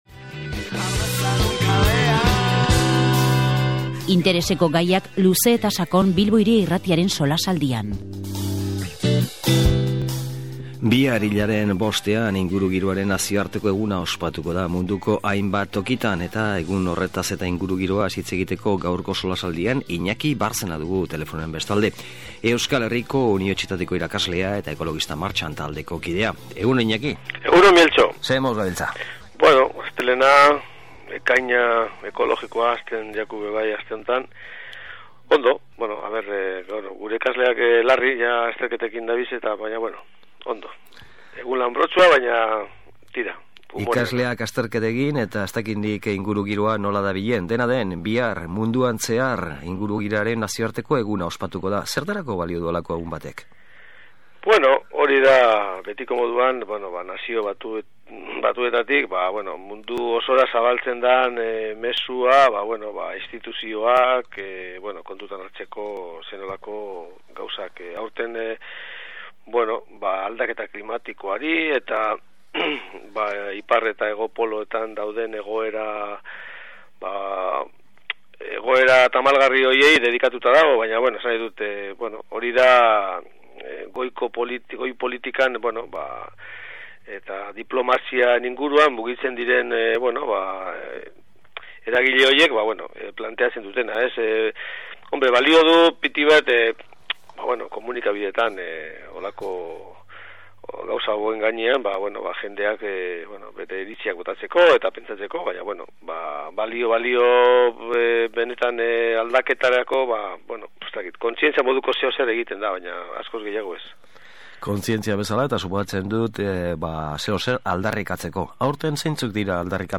SOLASALDIA: Ingurugiroaren nazioarteko eguna